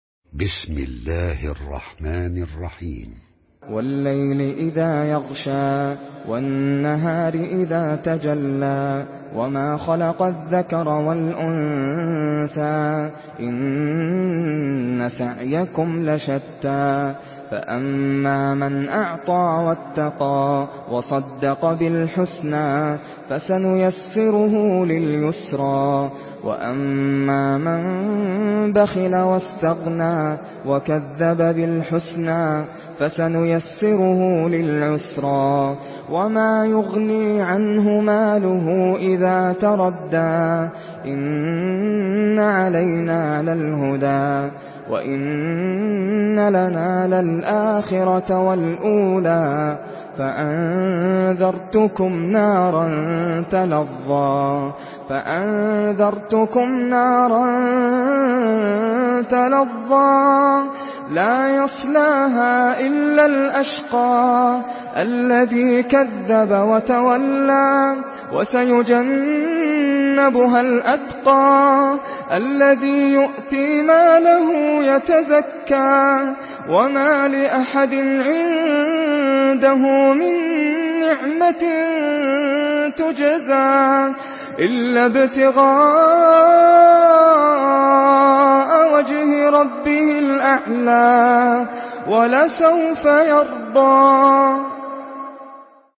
Surah আল-লাইল MP3 by Nasser Al Qatami in Hafs An Asim narration.
Murattal Hafs An Asim